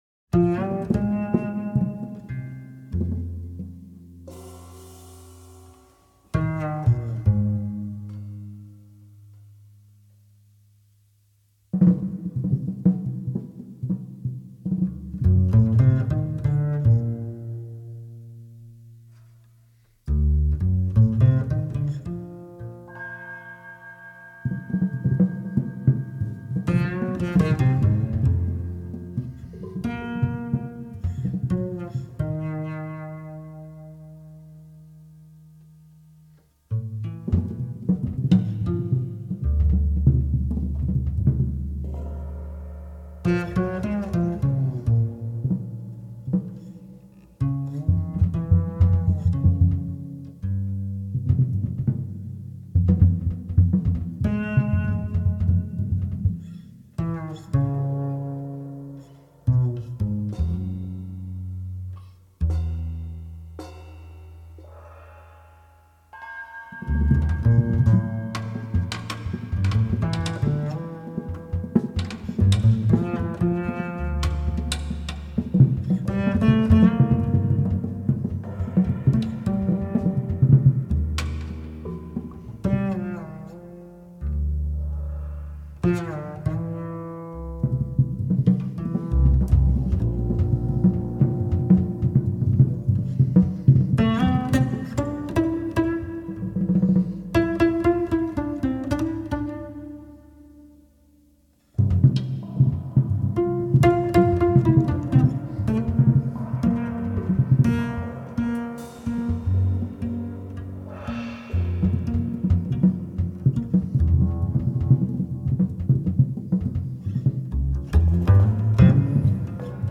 duo
bass
drums